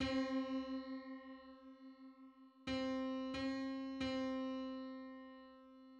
File:Septimal diesis on C.mid - Wikimedia Commons
49:48 = 35.7 cents.
Other versions File:Septimal diesis on C.png Licensing [ edit ] Public domain Public domain false false This media depicts a musical interval outside of a specific musical context.
Septimal_diesis_on_C.mid.mp3